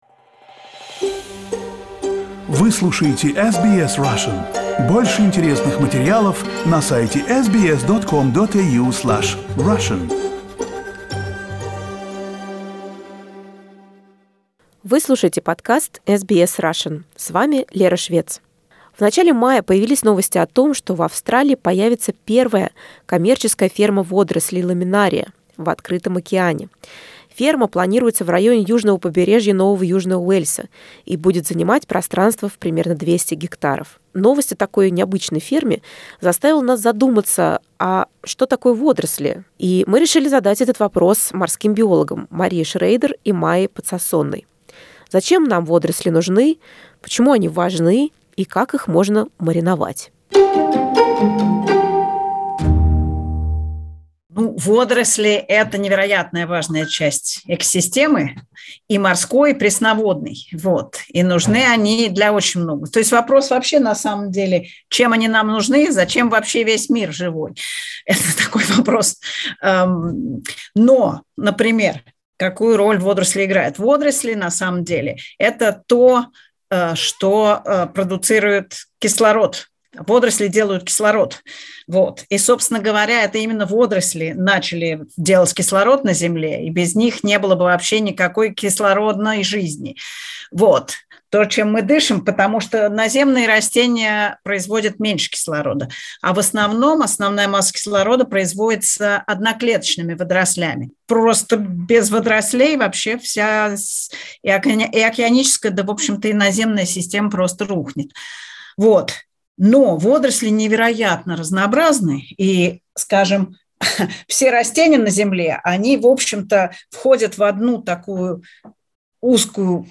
Морские биологи